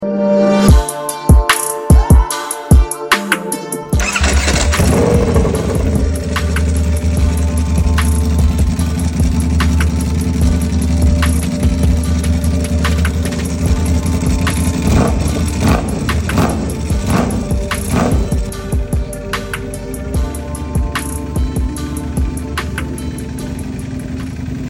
Ducati V4 2025 exhaust installation